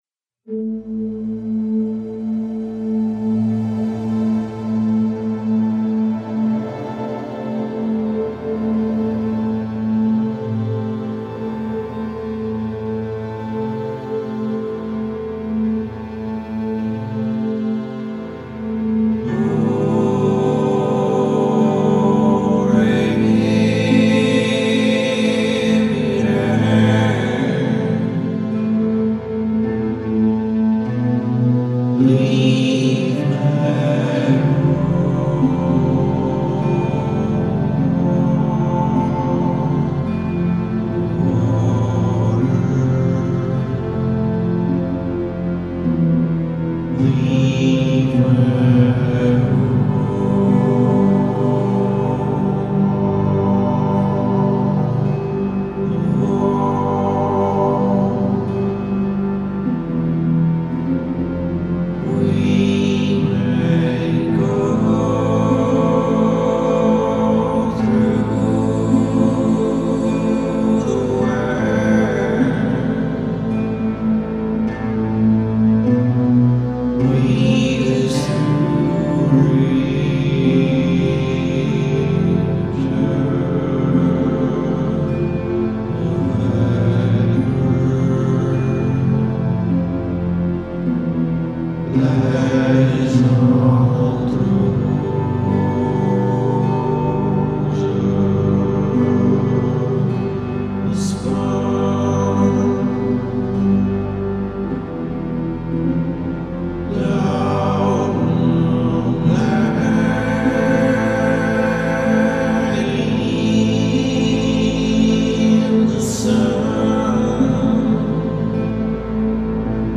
obsahuje čtyři dlouhý a hypnotický skladby
Four long tracks with the hypnotic and psychedelic vibe.